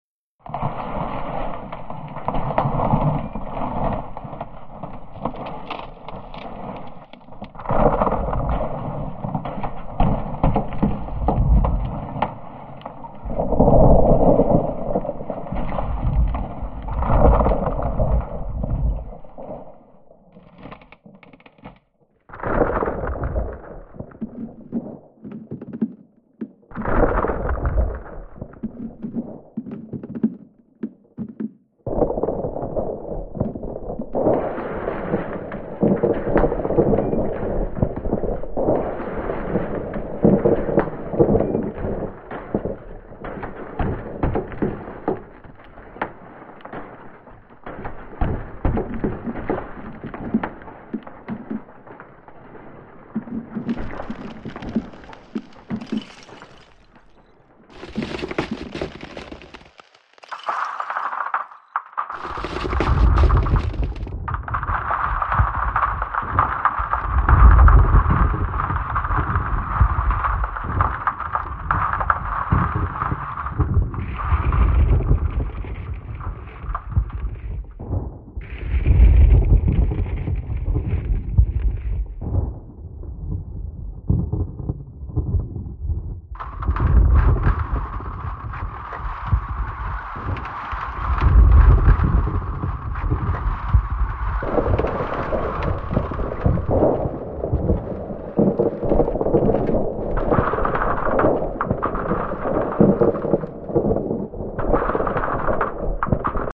Здесь вы найдете редкие записи: от глухого гула откалывающихся глыб до тонкого звона тающего льда.